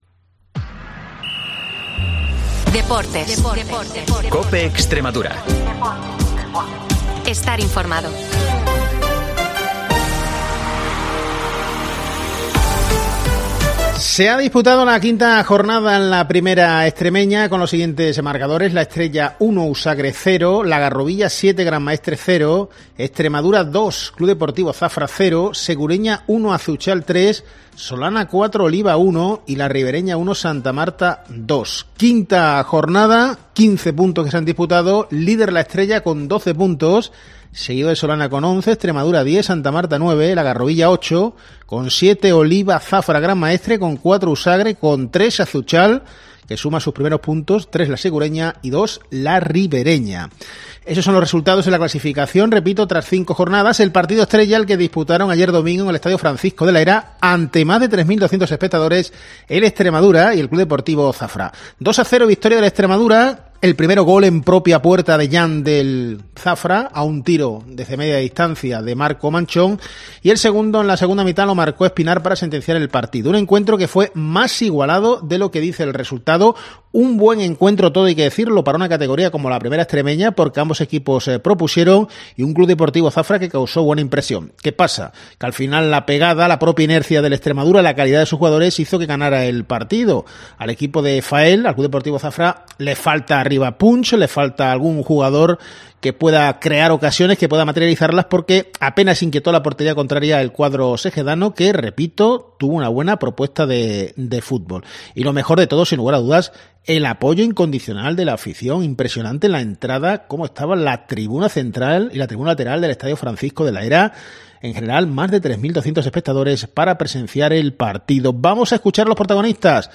Analizamos el partido, con audios de los protagonistas.